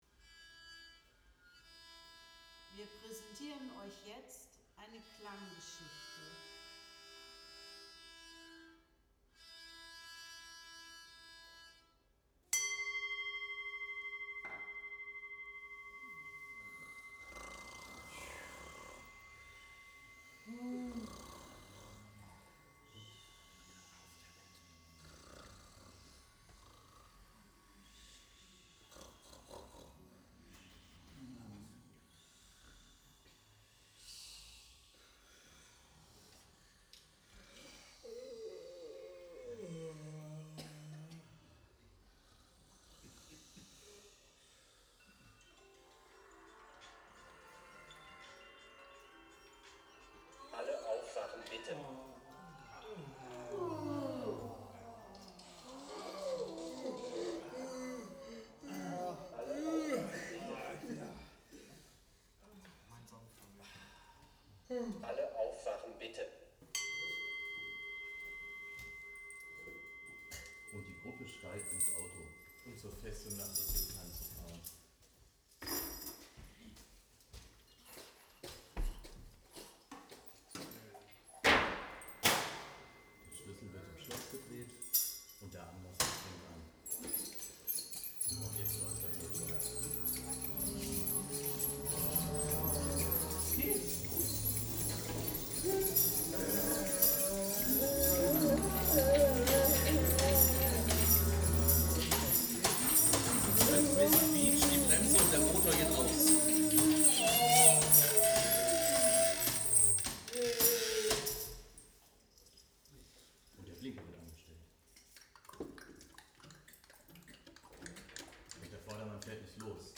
Klanggeschichten selbstgemacht: Geschichten und Bilder aus Tönen und Geräuschen
Anschließend experimentierten wir - eine Gruppe von jungen Erwachsenen aus dem Berufsbildungsbereich der WfB Rhein-Main e.V. - mit Alltagsgegenständen und selbst gebauten Klanginstrumenten.
Ziel des Projekts war es, gemeinsam experimentelle Klang-Musikstücke zu erarbeiten. Die Themen für die Klanggeschichten haben wir im Museum, bei der Arbeit oder in der Stadt gefunden. Zum guten Schluss wurden die selbst erdachten Klangstücke im Museum aufgeführt und aufgenommen. Für alle, die nicht dabei sein konnten, gibt es hier einen Mitschnitt der Geschichte „Erlebnisse im Museum“: